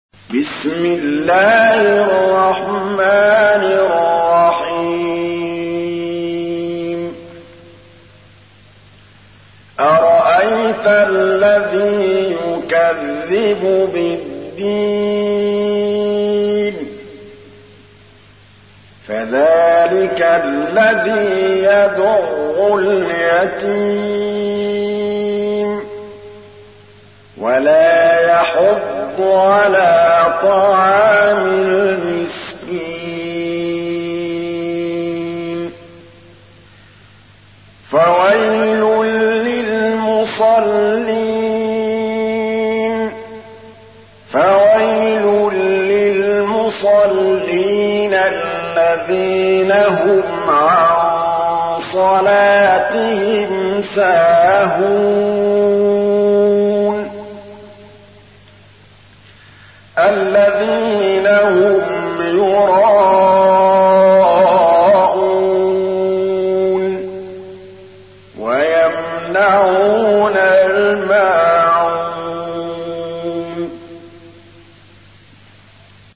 تحميل : 107. سورة الماعون / القارئ محمود الطبلاوي / القرآن الكريم / موقع يا حسين